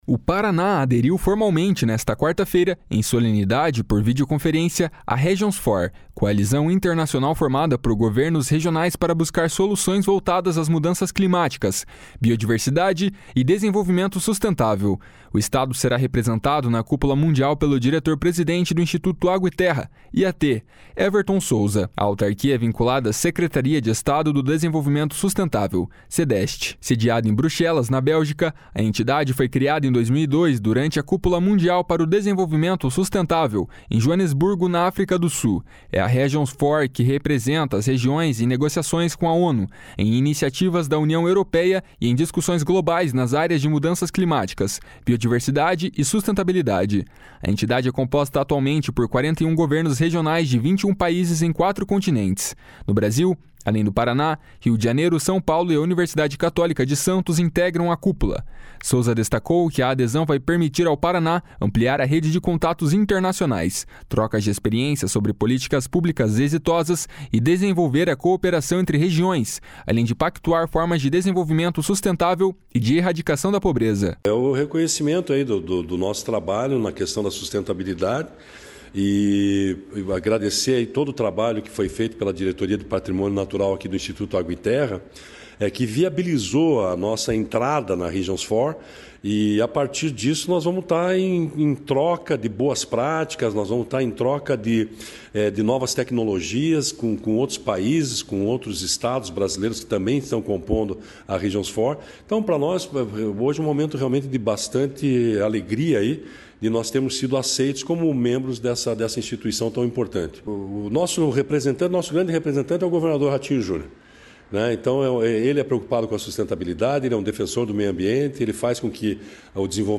// SONORA EVERTON SOUZA //